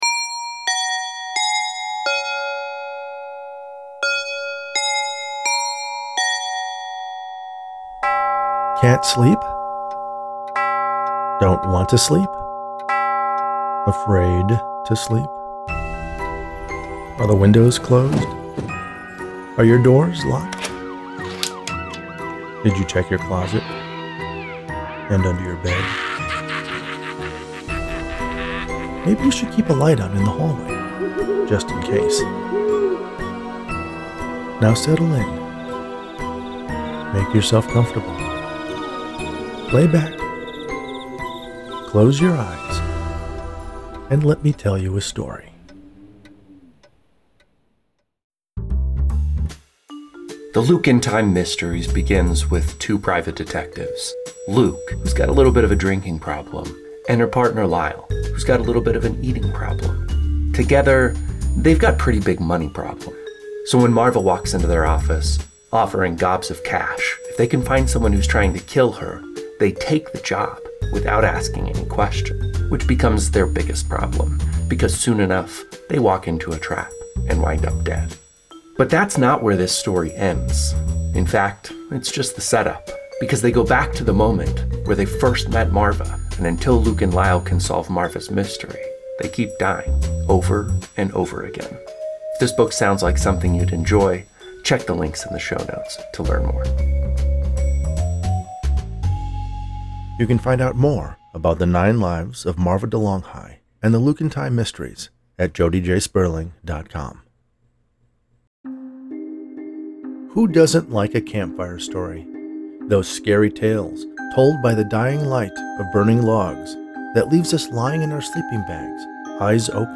A frightening little campfire horror story about a man, alone in a cabin in the woods, who opens his door to a stranger with a strange legend to share that turns out to be more than just a tall tale. horror mon